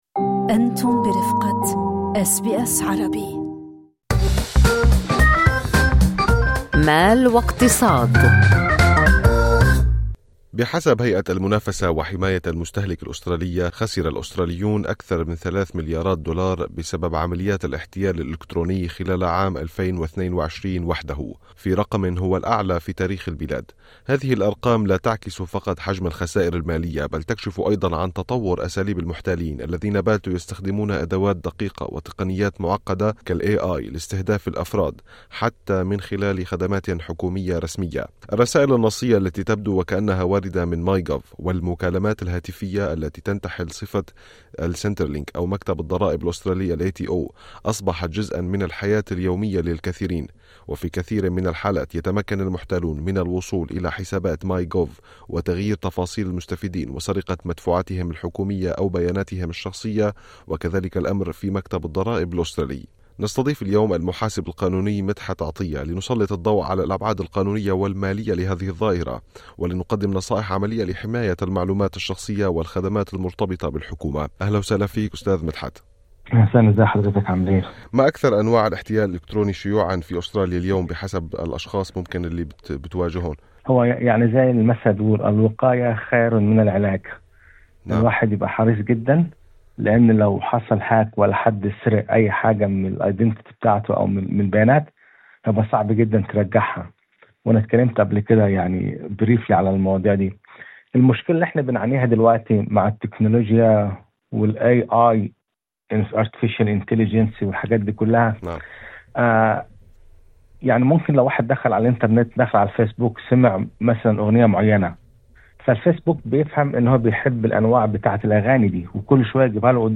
في لقاء مع المحاسب القانوني